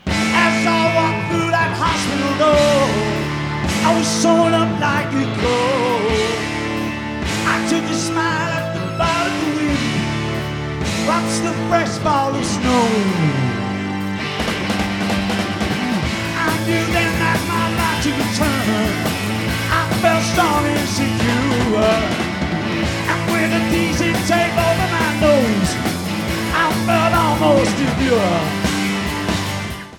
Portland (10-21-82) Radio Commercial(s) Master Tape